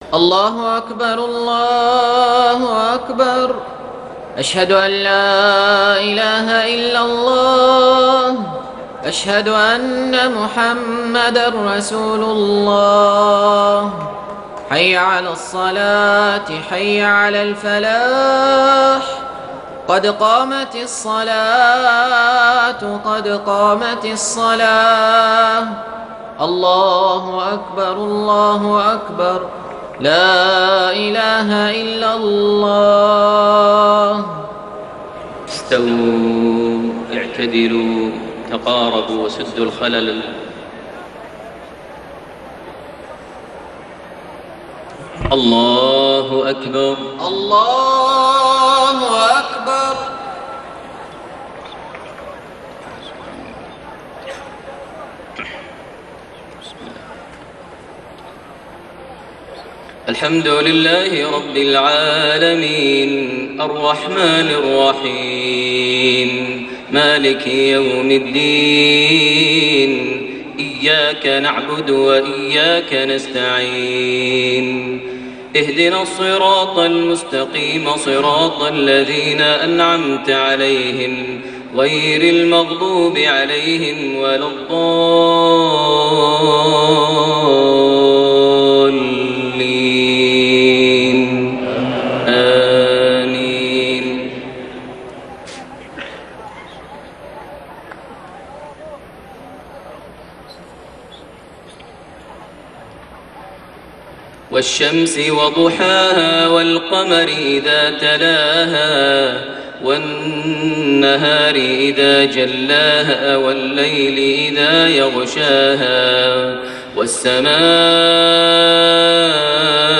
صلاة العشاء 2 شوال 1432هـ سورتي الشمس والليل > 1432 هـ > الفروض - تلاوات ماهر المعيقلي